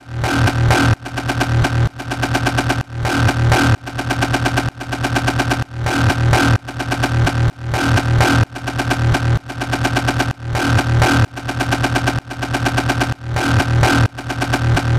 简单的低音线
描述：128 BPM。补习班/电音。
Tag: 128 bpm Electro Loops Bass Loops 2.52 MB wav Key : Unknown